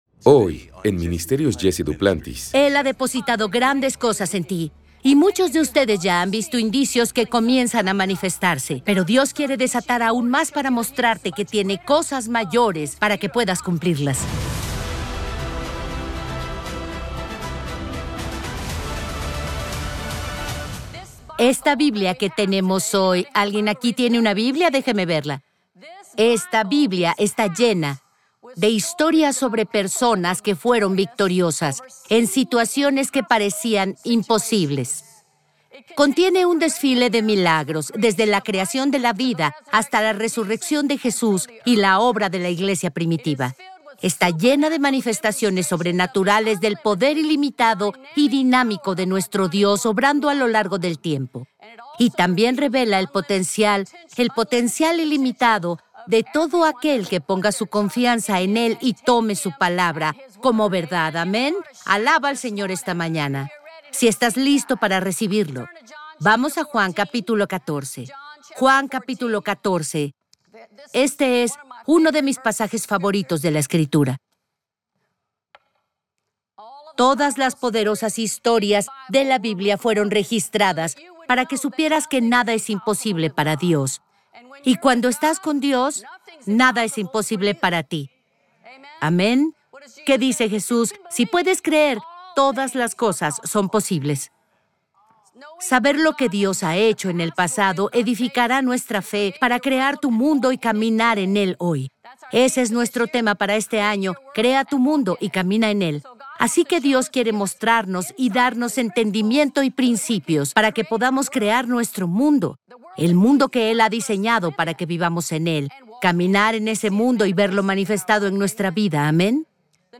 predica un mensaje